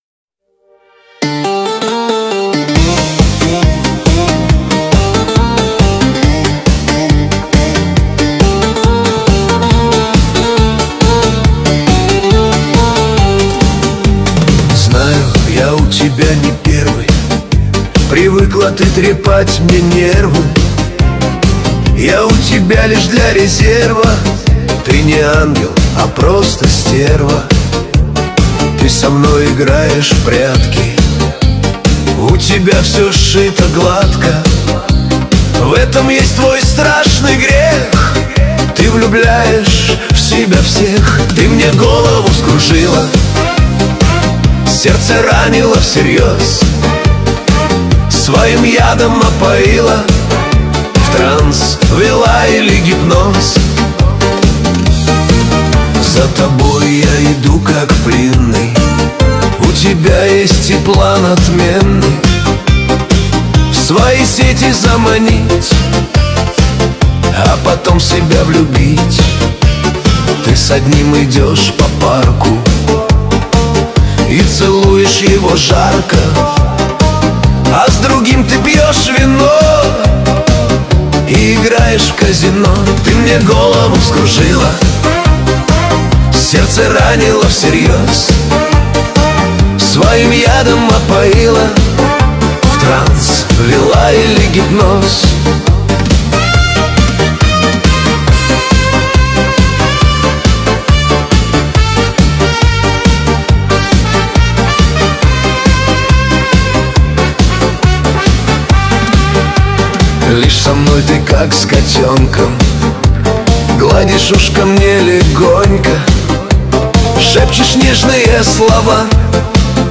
13 декабрь 2025 Русская AI музыка 130 прослушиваний